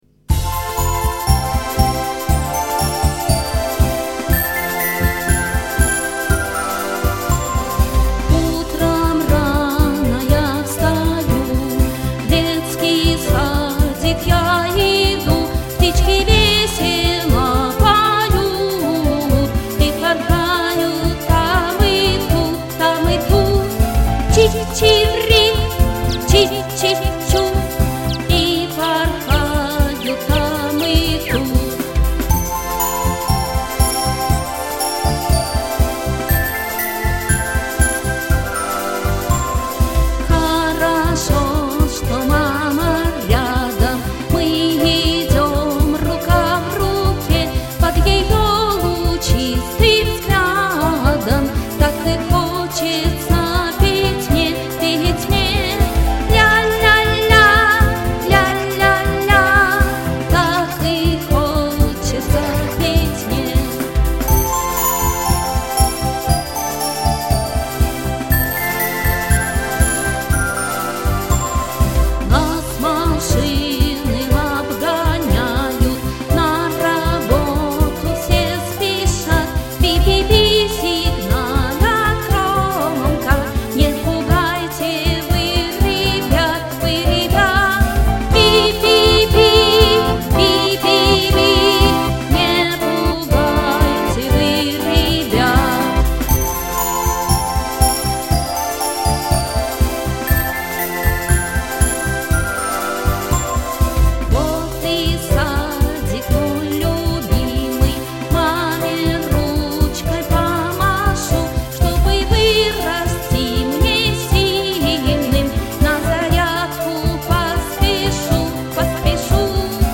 песня про детский сад.